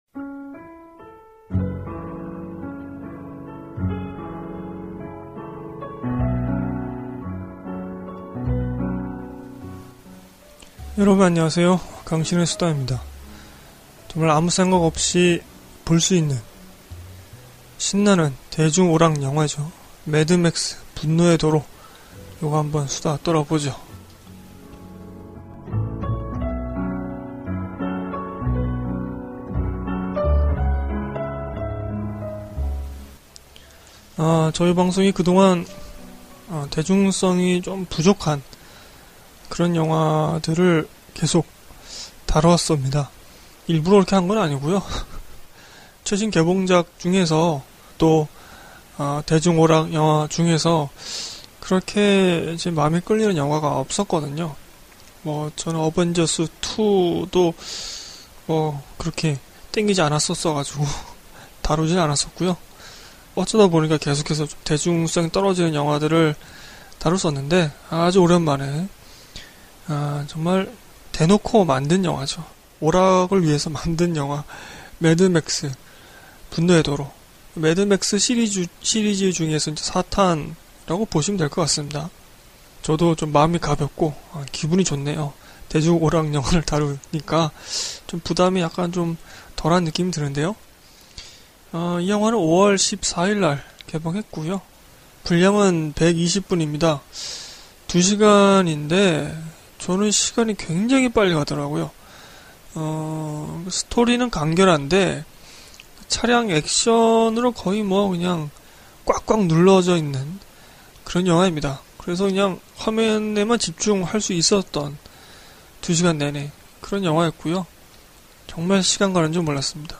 * 발음이 상당히 안 좋았네요 ㅠ 양해 바랍니다.